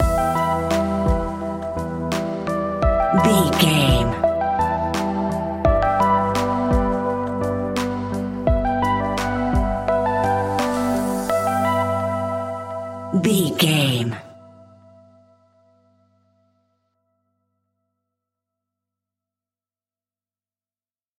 Aeolian/Minor
hip hop music
hip hop instrumentals
chilled
laid back
groove
hip hop drums
hip hop synths
piano
hip hop pads